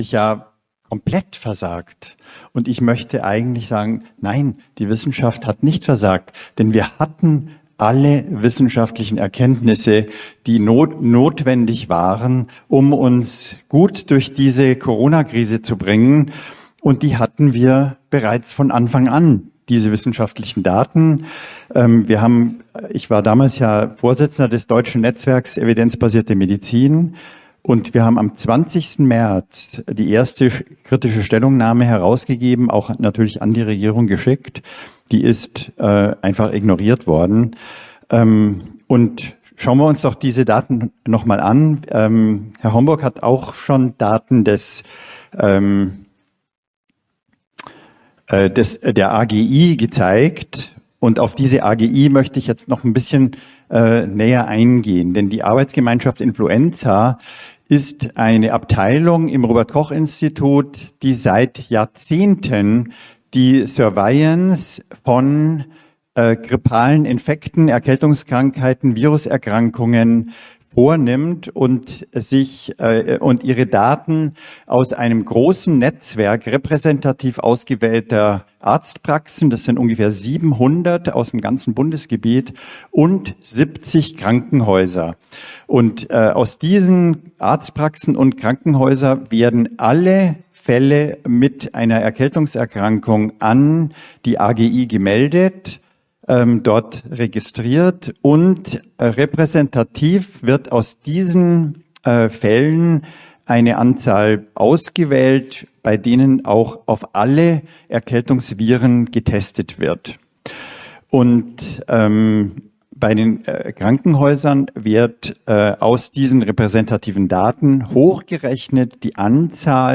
im 3. Corona Symposium der AfD im Bundestag